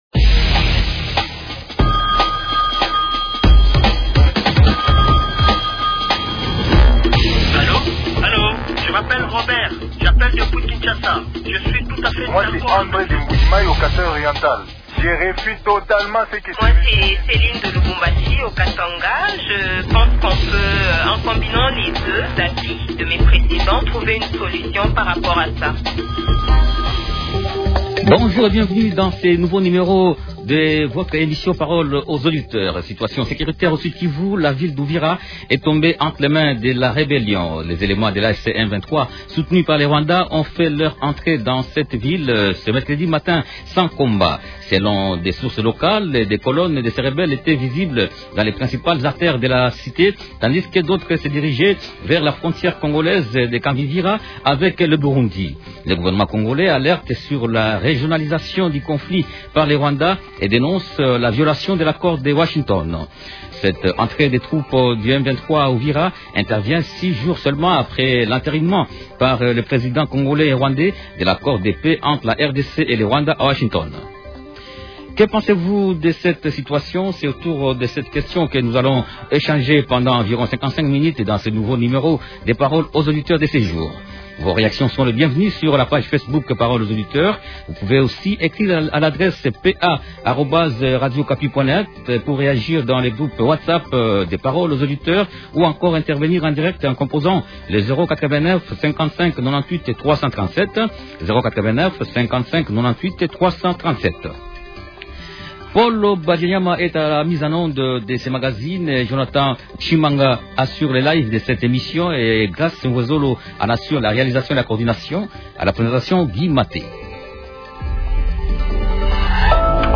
Invité: Willy Mishiki, député national élu de Walikale au Nord-Kivu.